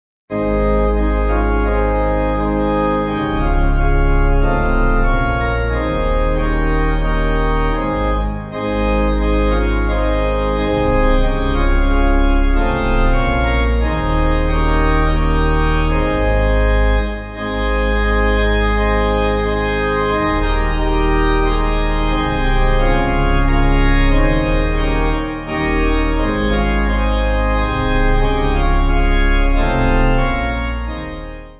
8.7.8.7.D
Organ
3/Ab